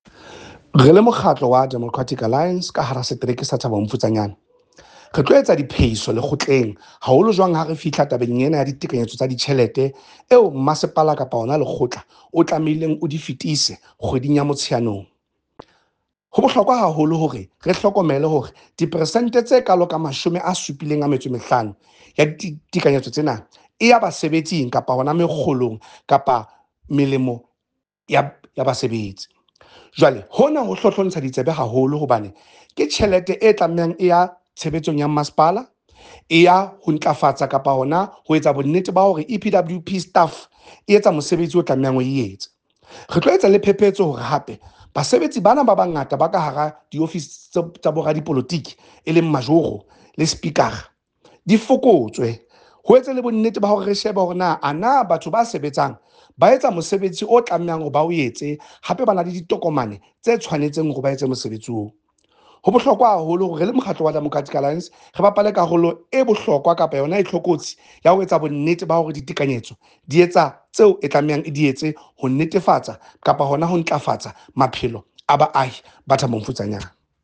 Sesotho soundbite by Cllr Eric Motloung